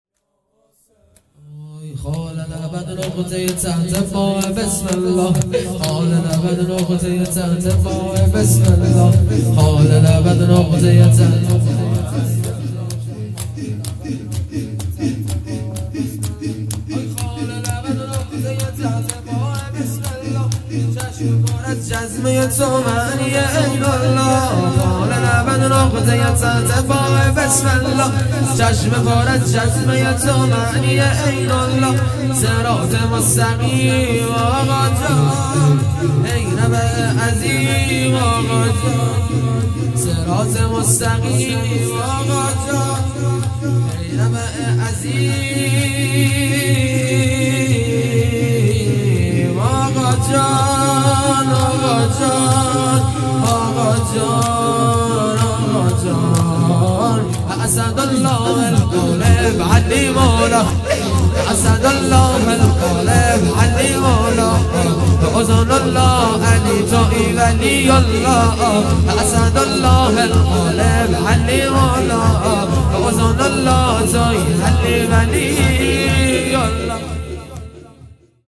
جلسه شهادت‌ حضرت زهرا سلام الله علیها